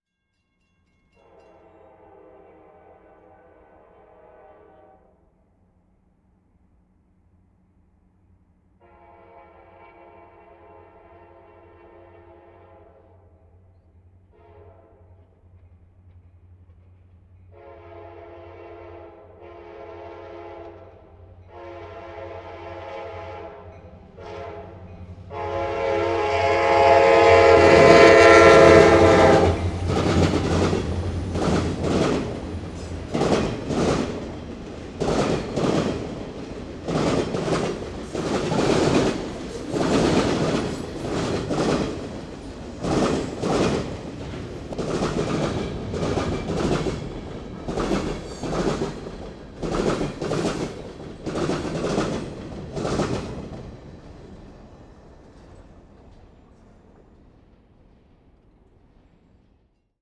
Tetrahedral Ambisonic Microphone
Recorded January 21, 2010, at the crossing of the Union Pacific and Austin and Western railroads, McNeil, Texas